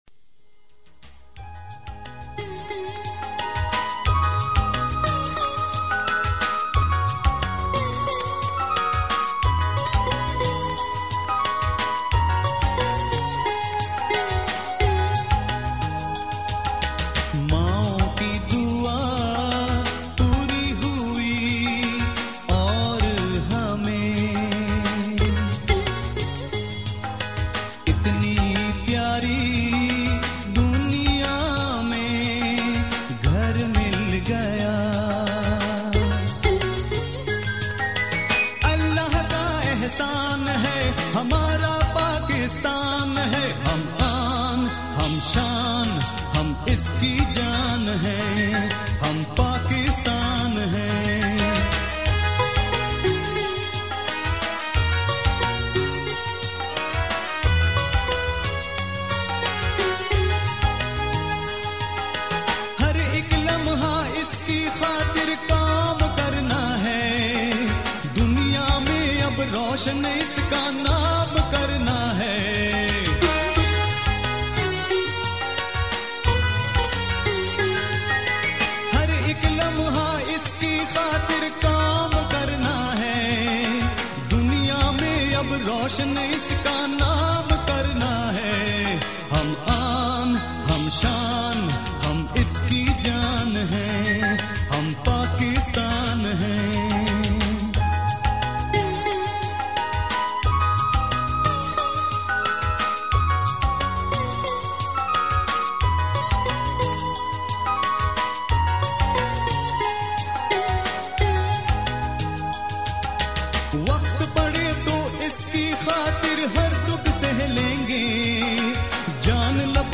national song